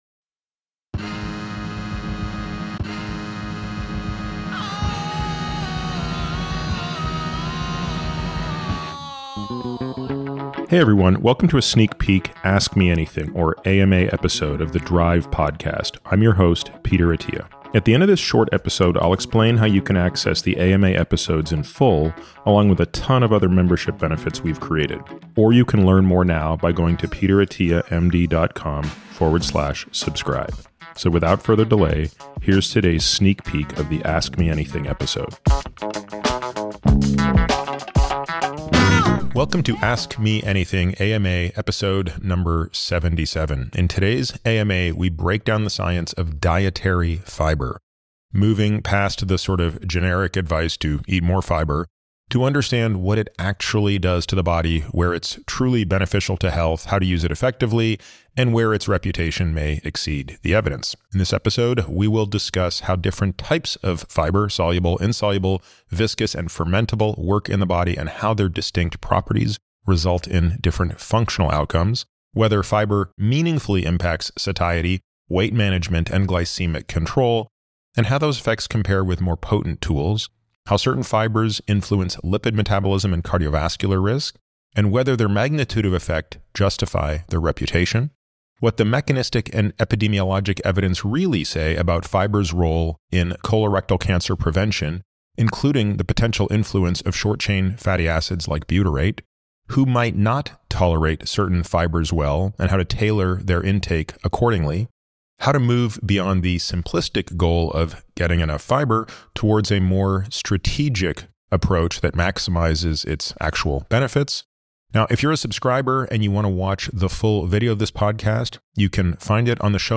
In this “Ask Me Anything” (AMA) episode, Peter breaks down the science of dietary fiber, moving beyond the blanket advice to “eat more fiber” to uncover what it actually does in the body and where its benefits are truly supported by evidence. He explains how different types of fiber—soluble, insoluble, viscous, and fermentable—affect digestion, satiety, weight management, and glycemic control, and compares their impact to other, more potent metabolic tools.